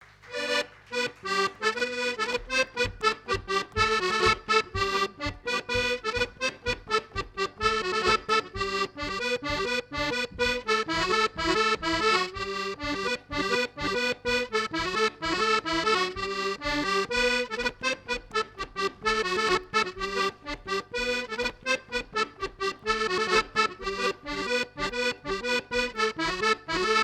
Chants brefs - A danser
Fête de l'accordéon
Pièce musicale inédite